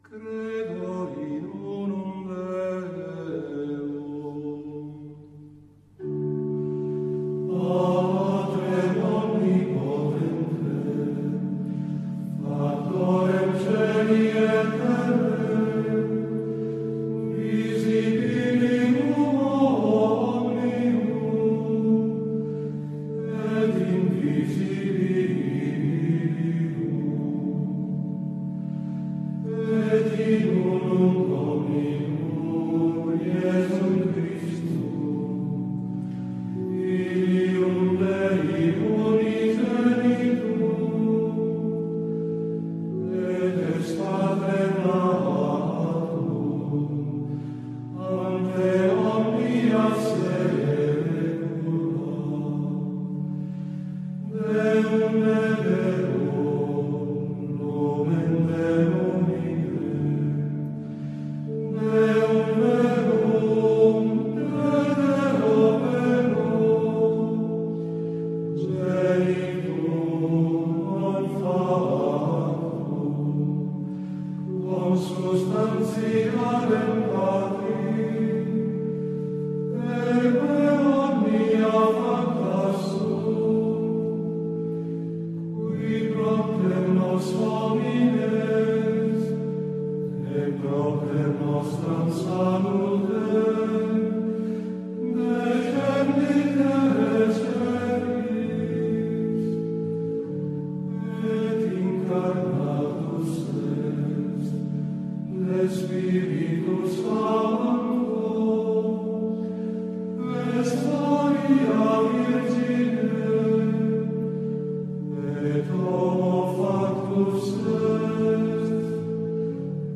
die schneekönigin muss noch etwas warten. weil diese musik jetzt vorrang hat. die musik, das sind gregorianische gesänge.
gregorian-gesang.mp3